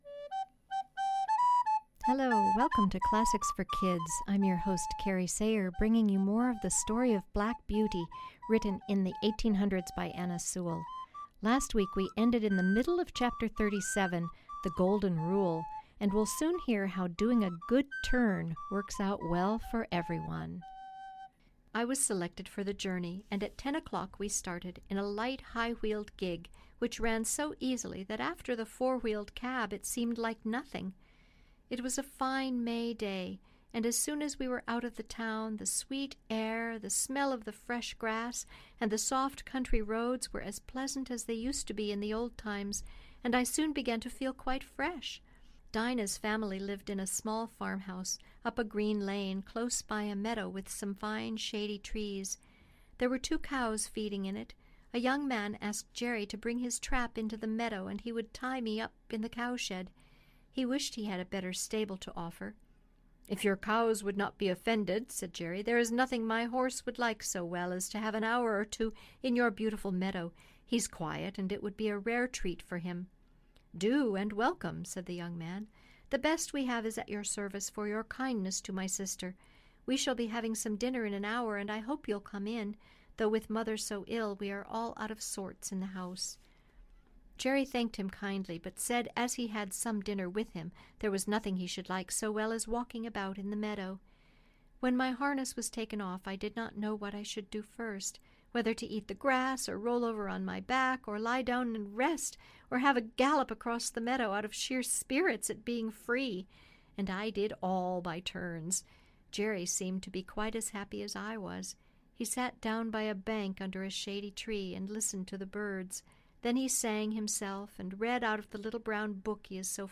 reading